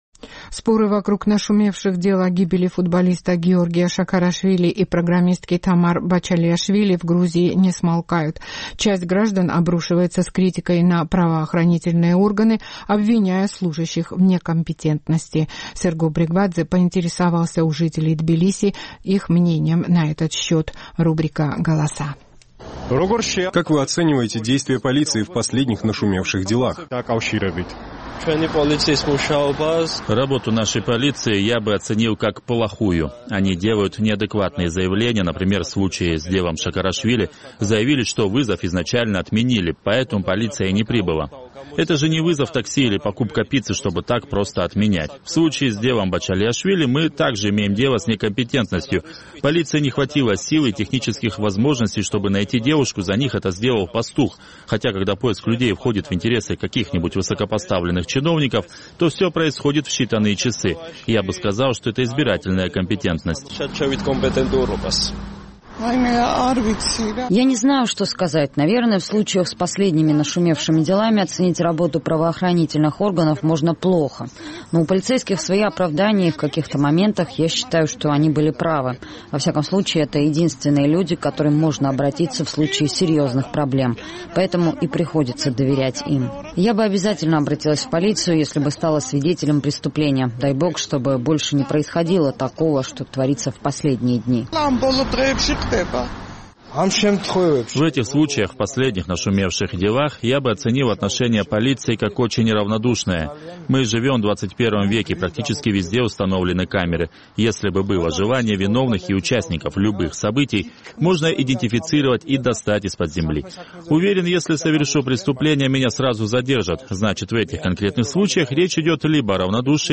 Голоса
Часть граждан обрушивается с критикой на правоохранительные органы, обвиняя служащих в некомпетентности. Мы поинтересовались у жителей Тбилиси мнением на этот счет.